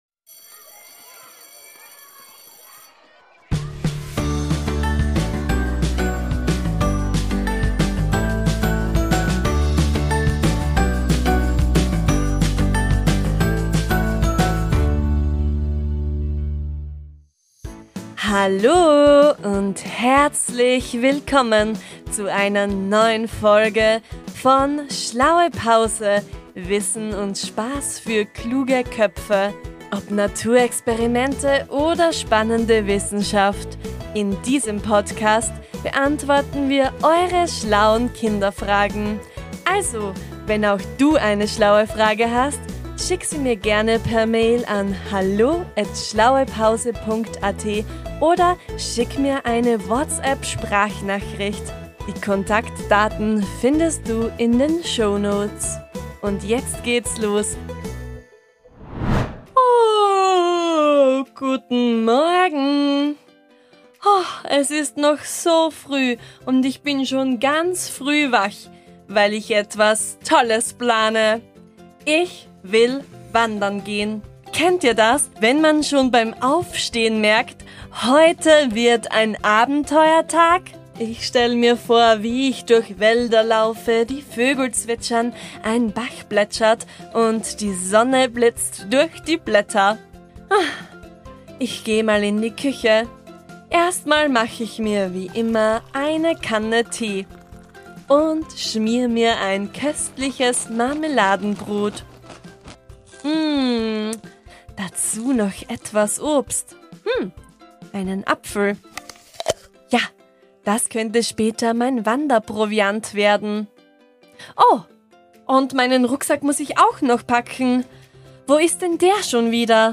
Kommt in dieser Kinderpodcast-Folge auf eine spannende Wandervorbereitung.
Am Ende gibt es ein cooles Quiz zum Mitmachen.